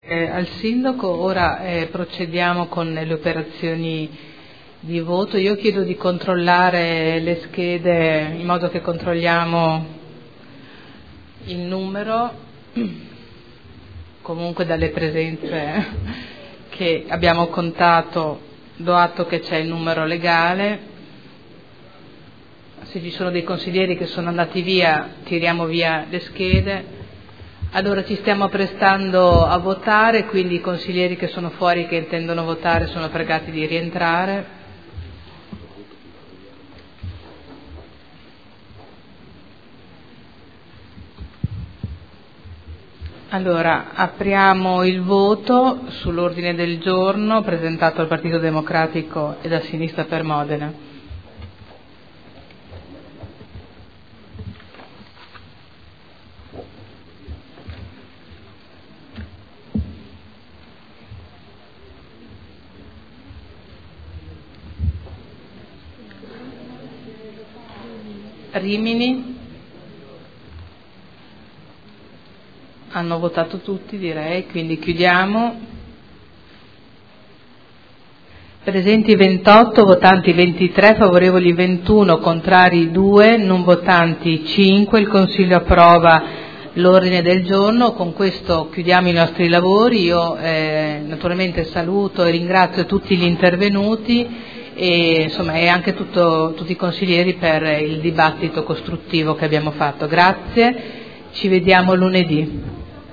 Seduta del 22/11/2012. Conclude dibattito e mette ai voti Ordine del Giorno su celebrazione dell’Anno internazionale delle cooperative indetto dall’ONU per il 2012.